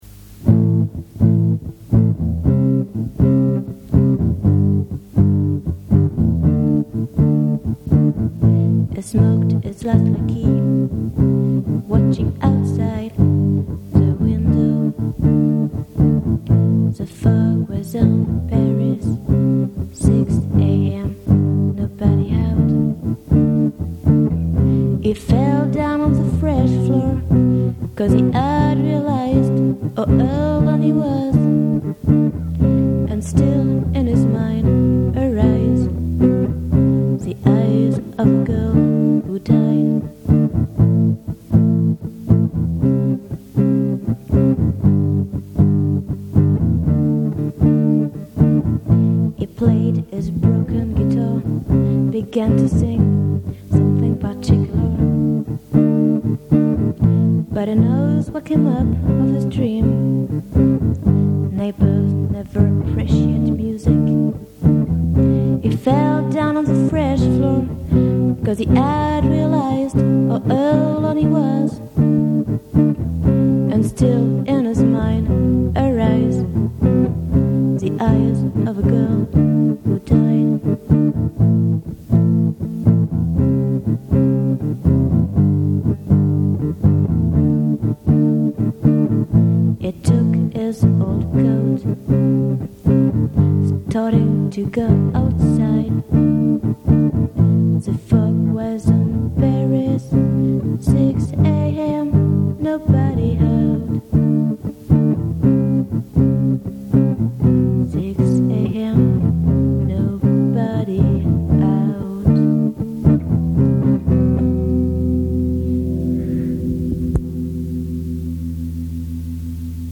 During this time I was trying to find the music on the guitar.